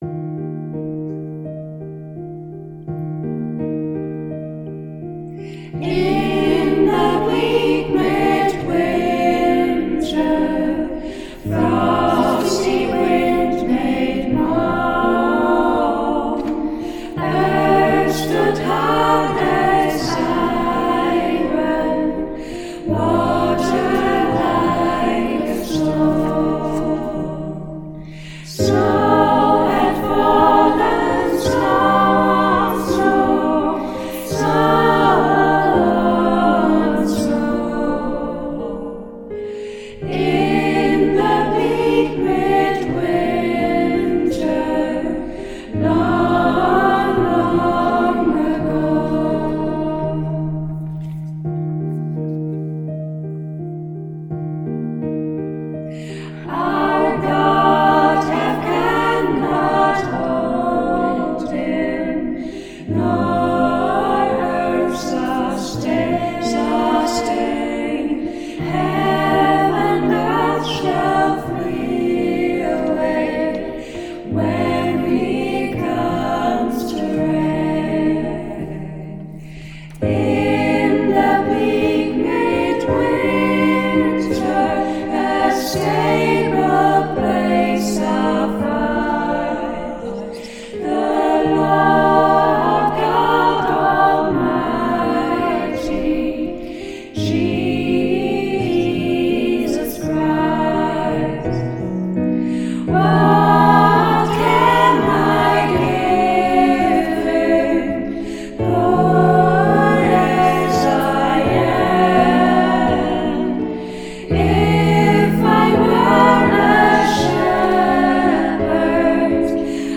Der Schülerchor des Gymnasiums Hittfeld
Für alle spannend war das finale Endprodukt: Schülerstimmen aus unterschiedlichen Kohorten verschmolzen digital zu einem gemeinsamen Chorklang.
xmas-grooves-in-the-bleak-midwinter-chor-1.mp3